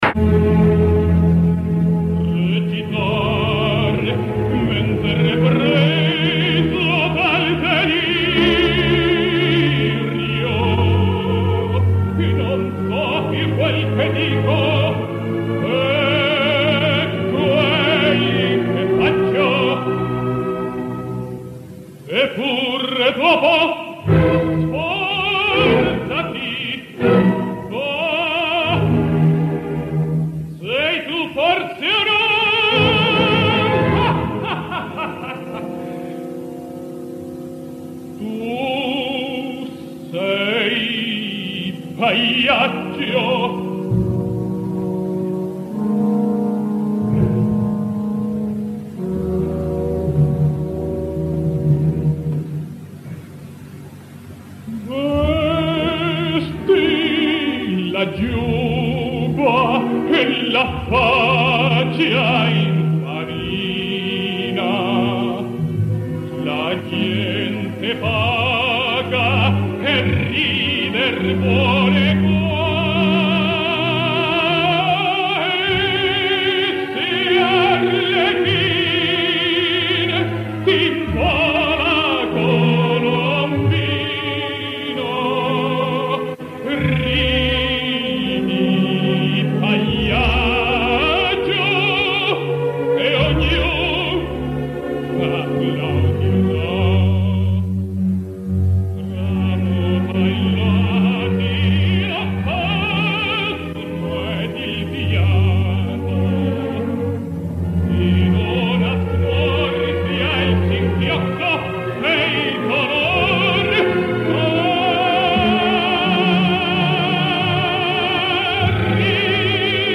Permeteu-me un parèntesi a la voràgine estiuenca de festivals, per fer una refleixó segurament estèril en motiu d’una gravació de l’any 1954.
A banda de la veu poc dramàtica o l’estil erroni, es poden apreciar ja unes virtuts que denoten estudi, tècnica, impostació, col·locació de la veu i emissió, control de la respiració amb un legato magnífic o una dicció claríssima, amb tot això als 27 ja tenia molt més que altres en plena maduresa o en tota la seva carrera, però en canvi segurament es va deixar aconsellar i va tenir el seny per no seguir per aquest camí.
Res més, us deixo amb aquest Alfredo Kraus sorprenent i juvenil, amb aquesta raresa que m’ha fet reflexionar sobre una cosa mil i una vegades parlada i discutida, però que davant d’un document com aquest, m’ha semblat oportú pensar-hi i deixar-vos sense la rabiosa actualitat, encara que això suposi per a molts com una mena de càstig, ja que avui els deixaré sense la dosi diària de material sensible.